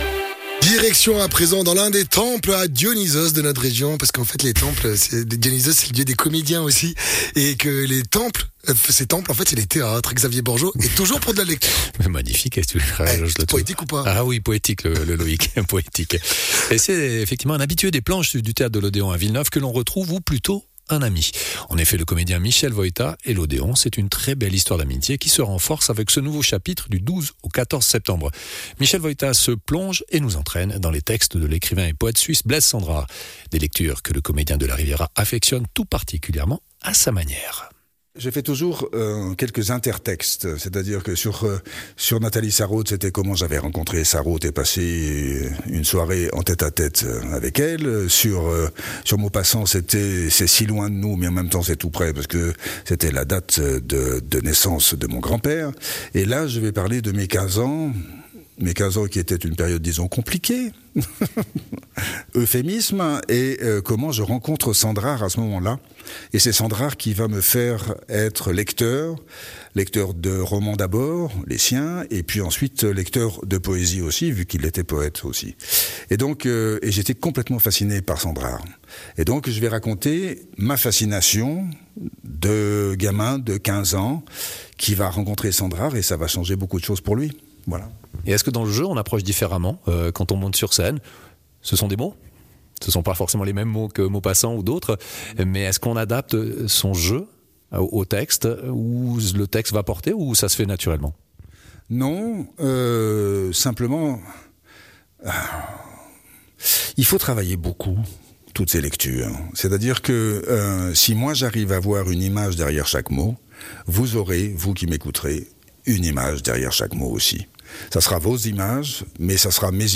comédien et acteur